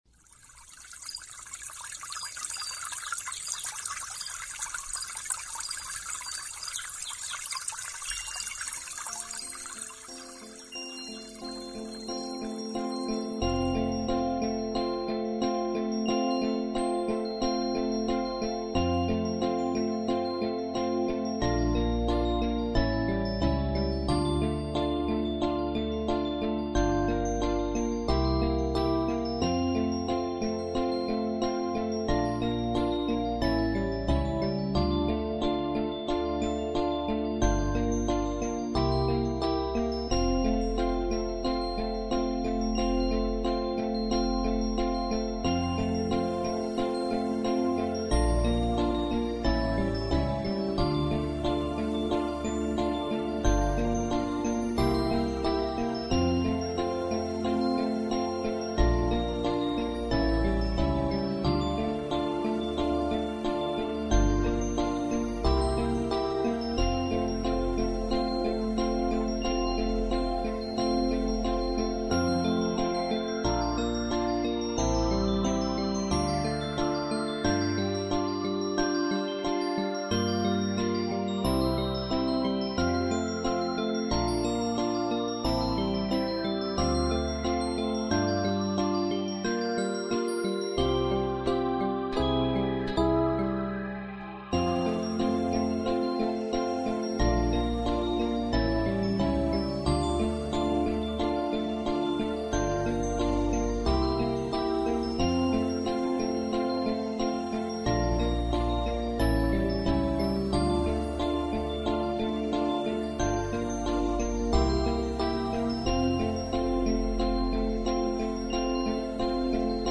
Колыбельные песни